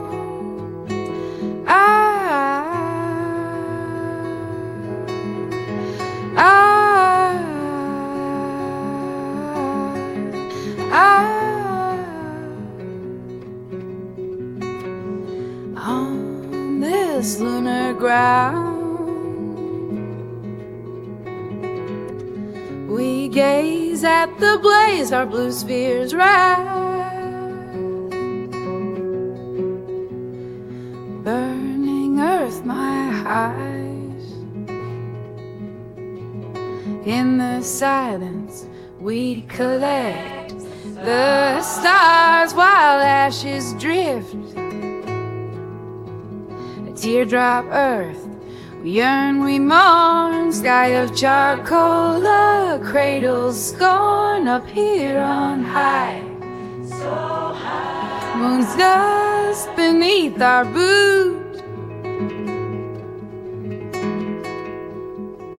acoustic rock song about living on the Moon while Earth is on fire